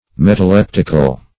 Metaleptical \Met`a*lep"tic*al\